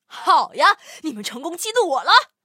M41中破语音.OGG